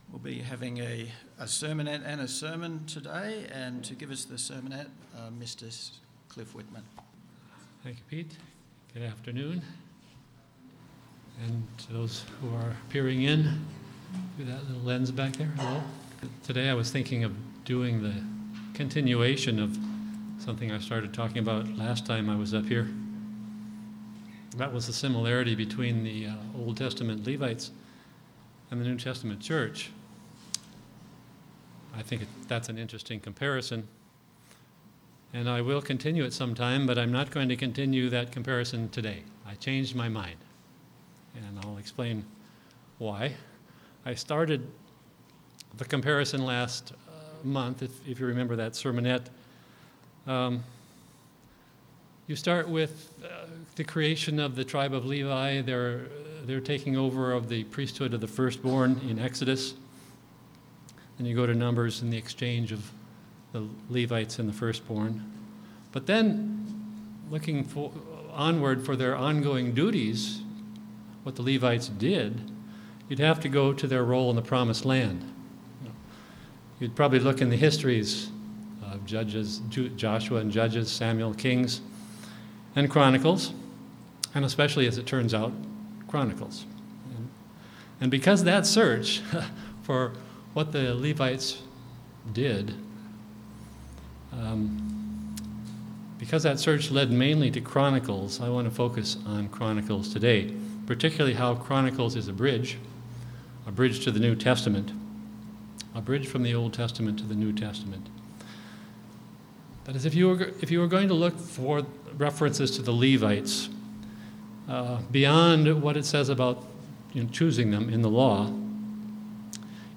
The book of Chronicles is a bridge from the Old Testament to the New. This sermonette is an brief outline of a sermon that goes into the subject more thoroughly.